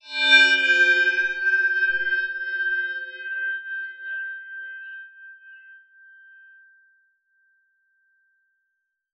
metallic_glimmer_drone_03.wav